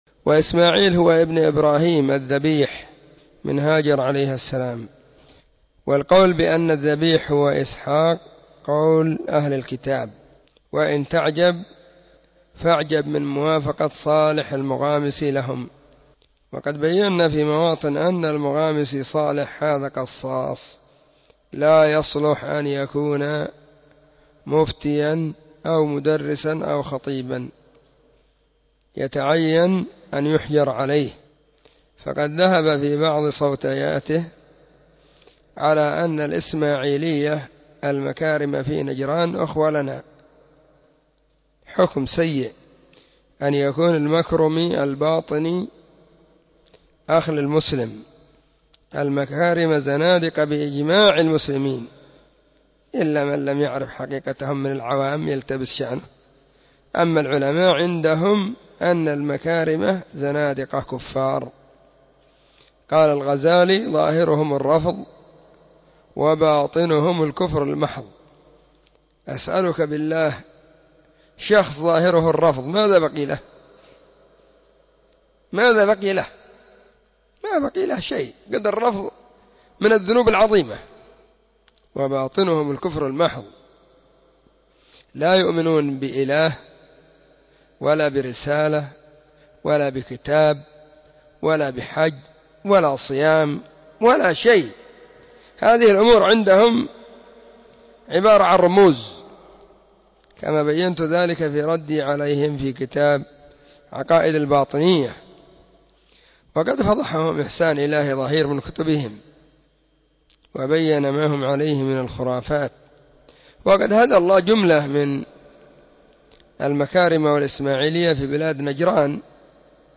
📢 مسجد الصحابة – بالغيضة – المهرة – اليمن حرسها الله.